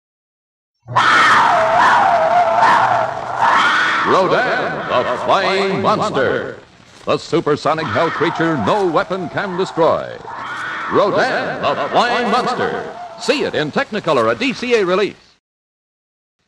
The Flying Monster Radio Spots
20, 30, and 60 seconds radio spots for Rodan!